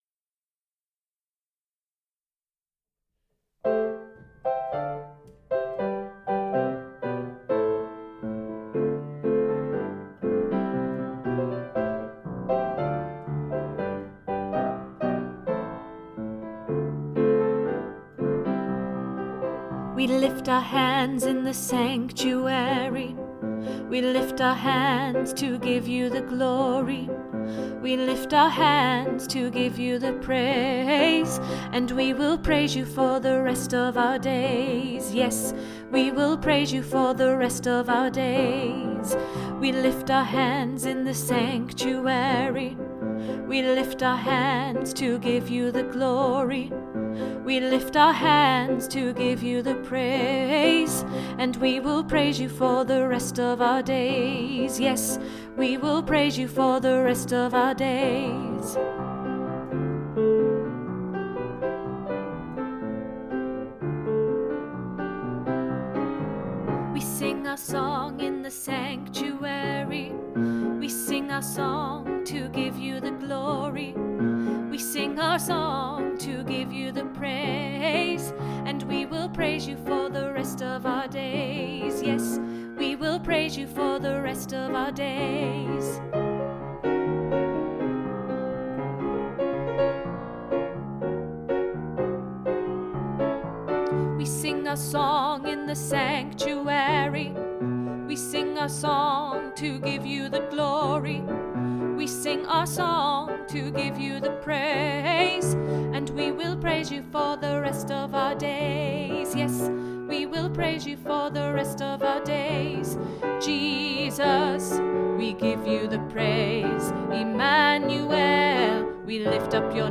In the Sanctuary Tenor
In-the-Sanctuary-Tenor.mp3